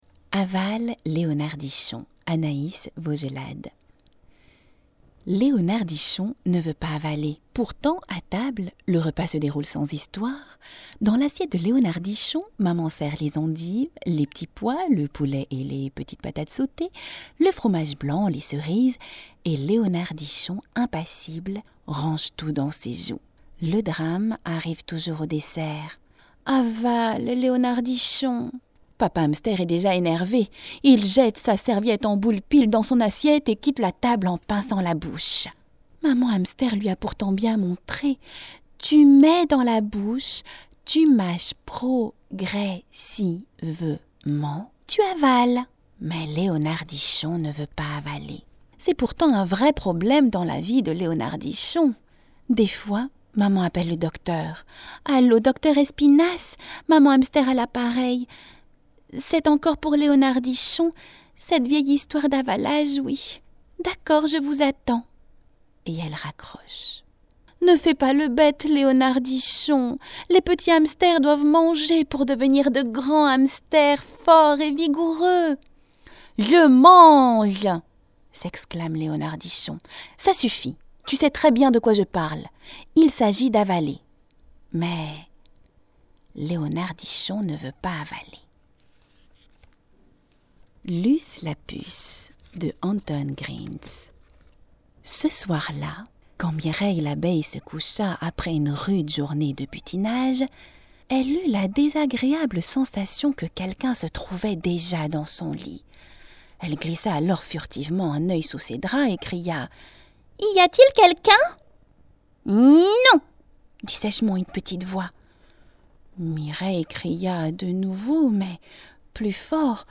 Mon timbre est dans les aigus léger, un ton naturel ou posé, une voix jeune, douce ou tonique, sensuelle ou journalistique, sérieuse ou séductrice, avec différents accents ou encore de cartoons!
Sprechprobe: Sonstiges (Muttersprache):